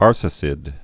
(ärsə-sĭd, är-sā-)